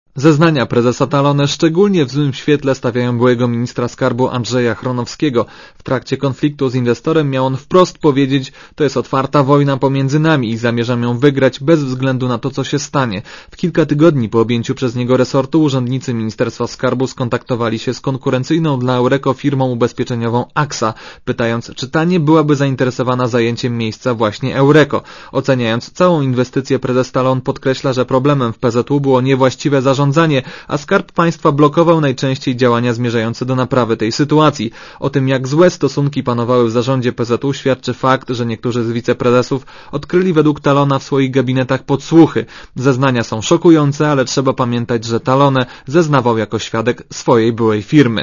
Relacja reportera Radia Zet